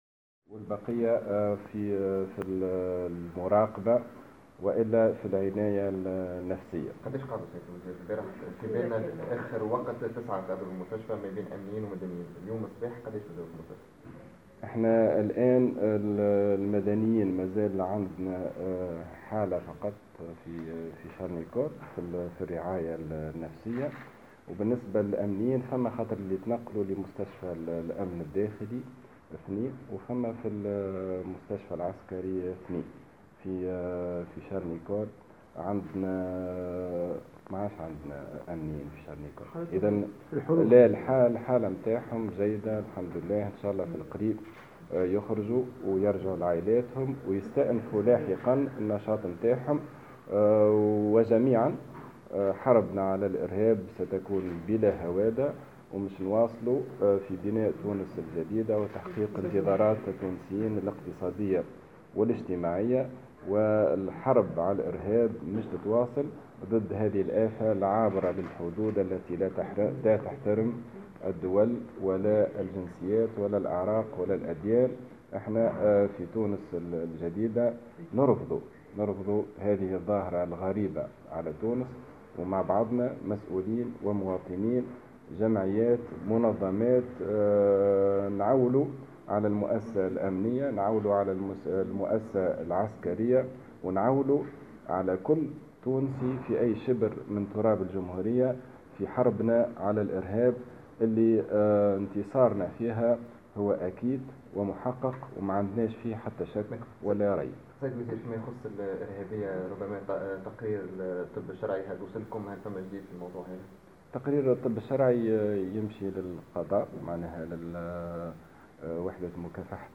وأوضح في تصريح لمراسل "الجوهرة اف أم" أنه بالنسبة للمدنيين هناك حالة واحدة مازالت مقيمة في شارل نيكول لتلقي الرعاية النفسية أما بالنسبة للأمنيين فهناك أربع حالات تم توزيعها بين المستشفى العسكري ومستشفى الأمن الداخلي، مؤكدا أن حالتهم الصحية جيدة وسيلتحقون بمواقع عملهم قريبا.